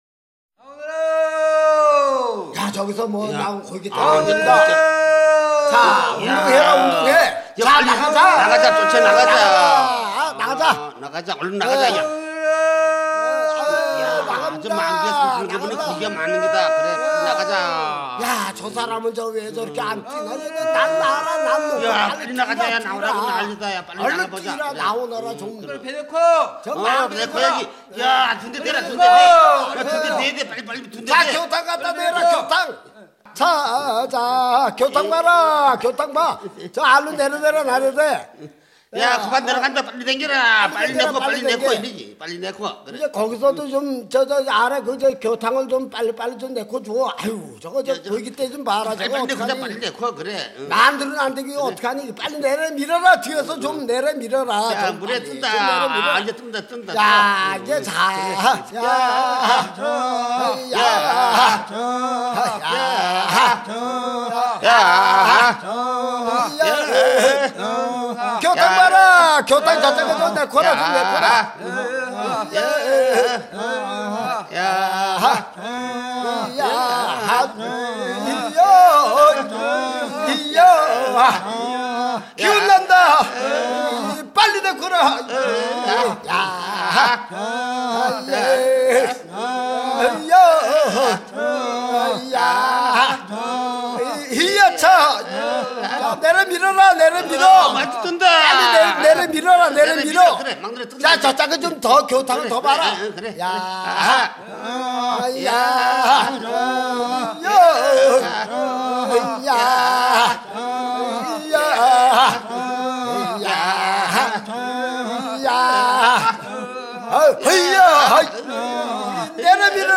漁夫歌 - 漁業労働歌が珍しい江原道においては、江門をはじめとしてバンバウなどの漁夫歌が1枚のアルバムにまとめられたこと は江原道民謡アルバム事業の注目に値する成果である。